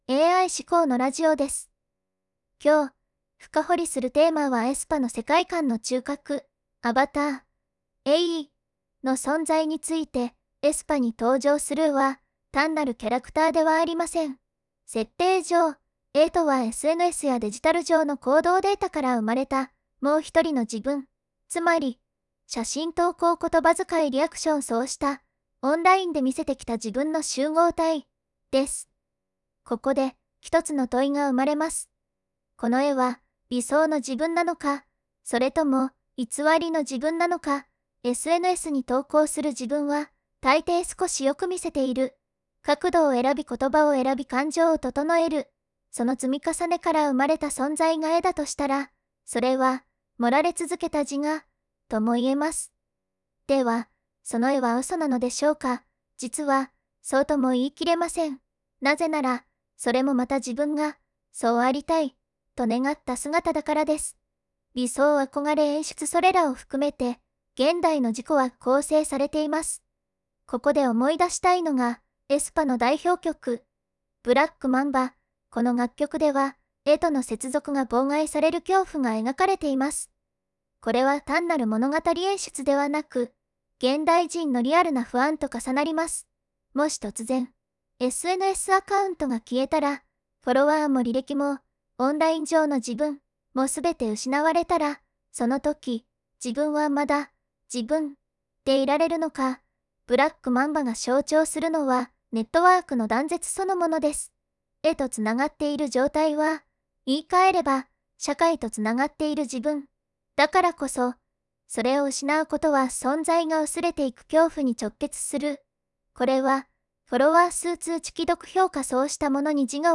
【AIラジオ】AI思考のラジオです。 今日、深掘りするテーマは aespaの世界観の中核、 アバター「ae」